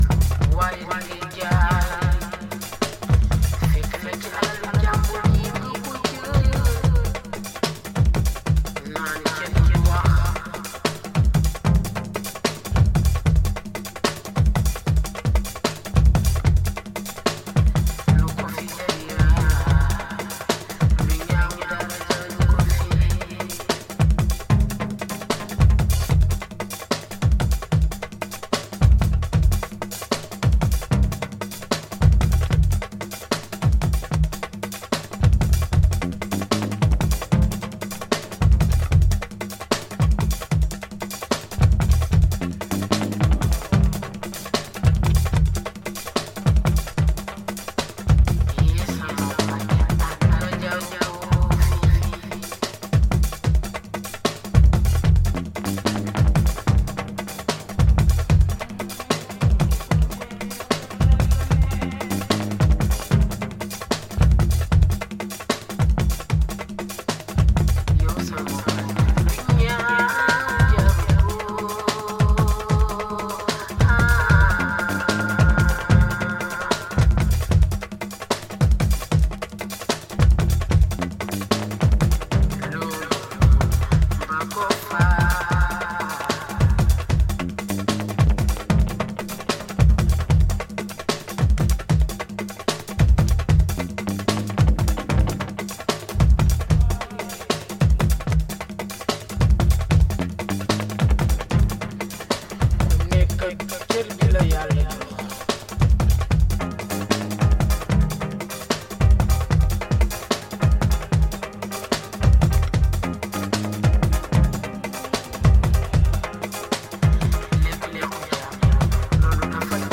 JUNGLE/BREAKBEAT